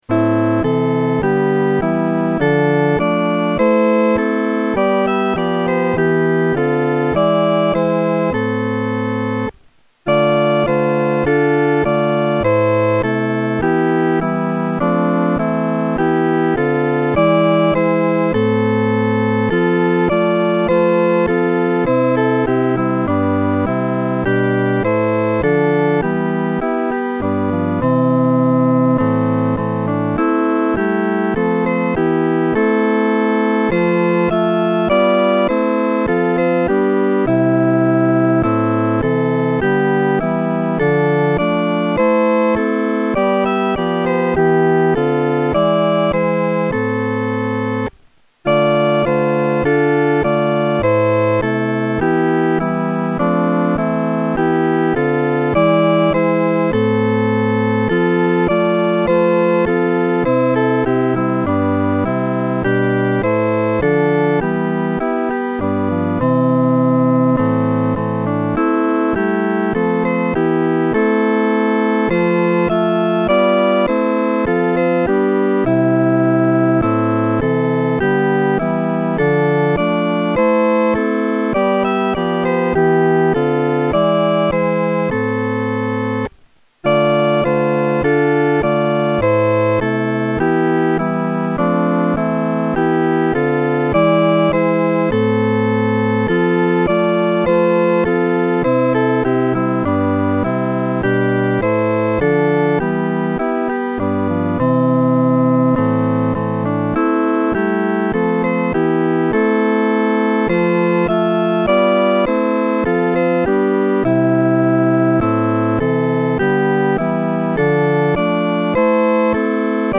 伴奏
四声
曲调开始是商调，非常有力，接着转为羽调，作了一个肯定；然后又转为宫调，有一个稳定的半终止。
这首圣诗的弹唱速度不宜太拖沓。